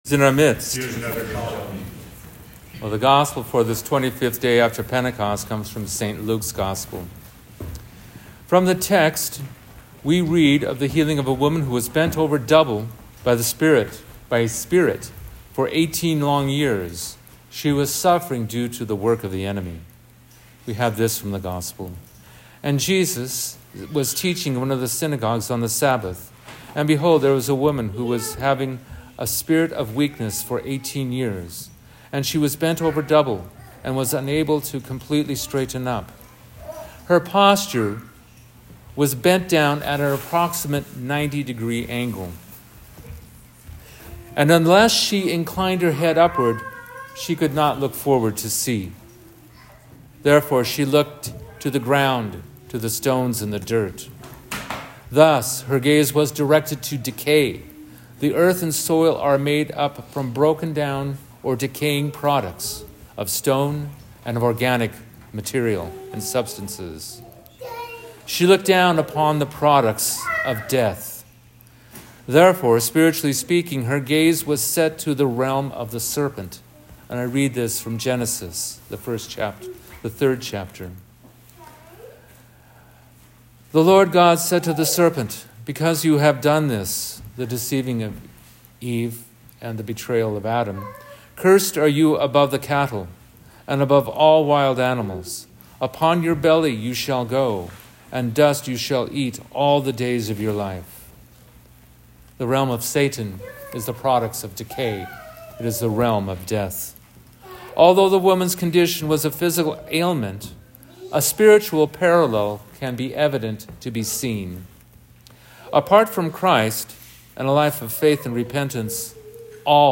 Here is the corresponding sermon given at St Jacob of Alaska Orthodox Church in Bend, Oregon on 11/30/25: